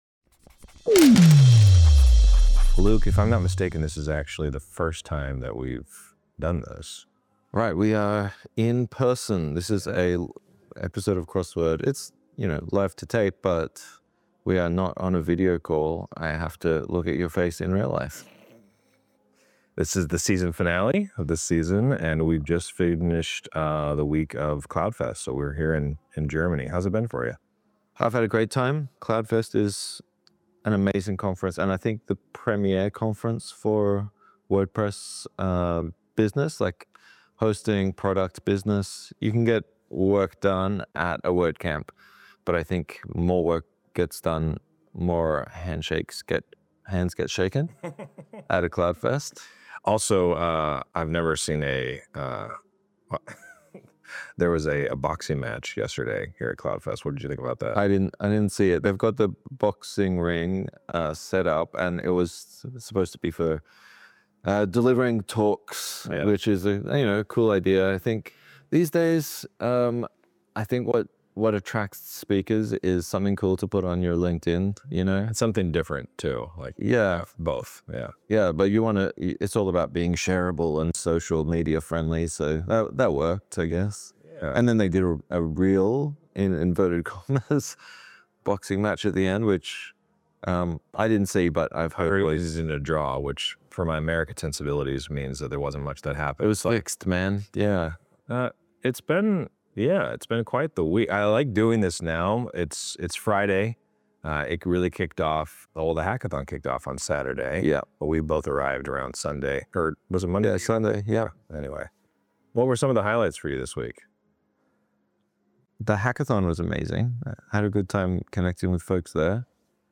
This season finale is the first episode of Crossword to be recorded in person.